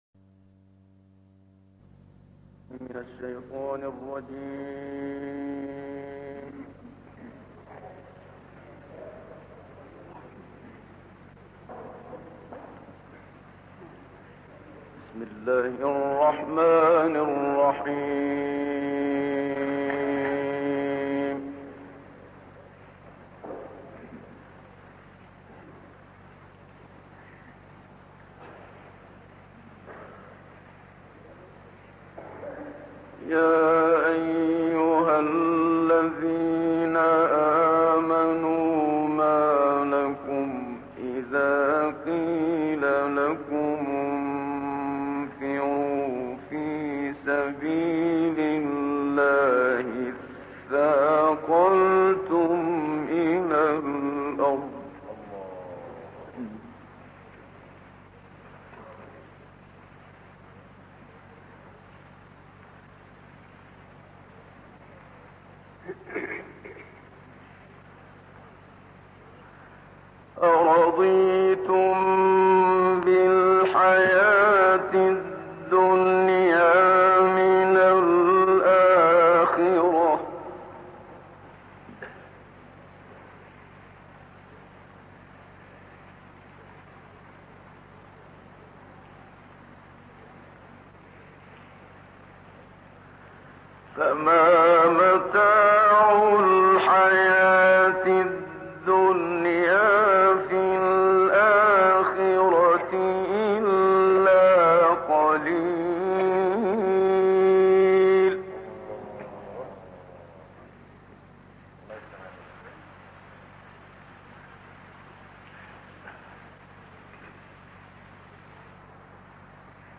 تلاوة خالدة للشيخ "محمد صديق المنشاوي".. سورة التوبة في المسجد الأقصى
تلاوات معطرة - الكوثر: من أروع تلاوات القارئ الكبير الشيخ محمد صديق المنشاوي، تلاوة عطرة من سورة التوبة (38-43) في المسجد الأقصى.